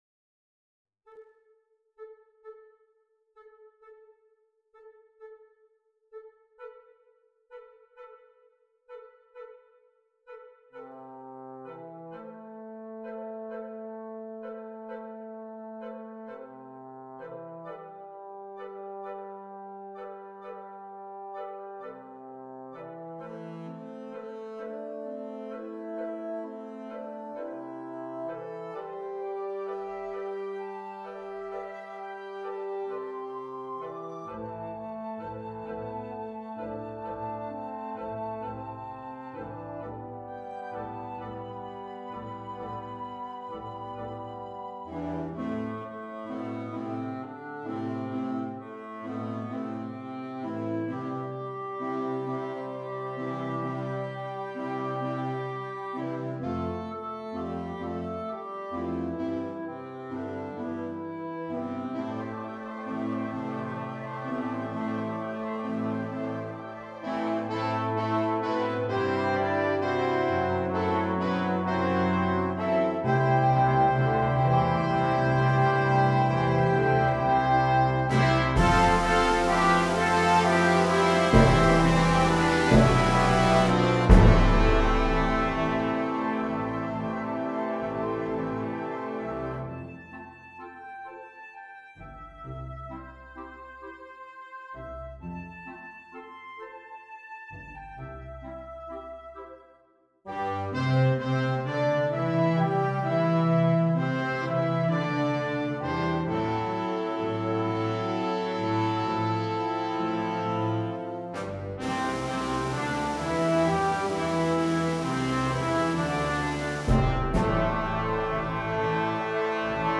• Piccolo /Flauta 3
• Oboe
• Clarinete en Bb 1
• Saxofón Alto 1
• Trompeta en Bb 1
• Corno en F 1
• Trombón 1
• Tuba
• Timbal
• Glockenspiel